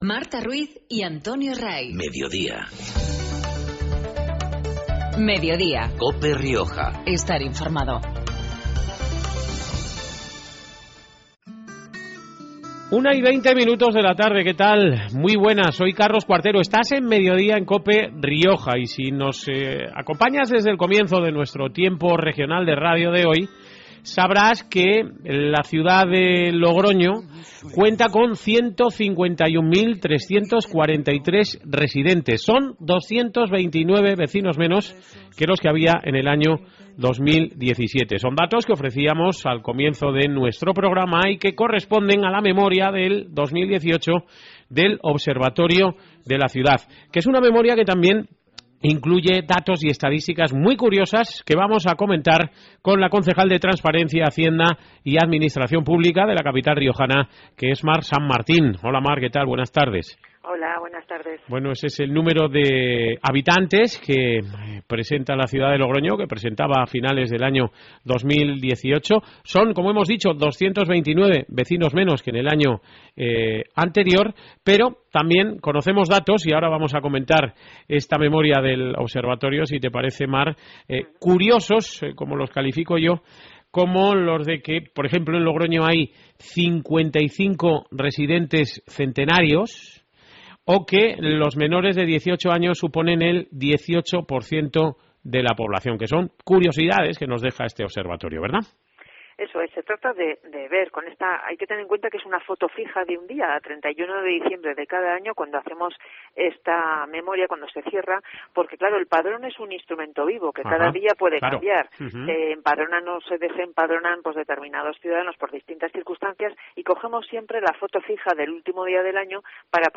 Hoy con Mar San Martín, concejal de Transparencia en Logroño.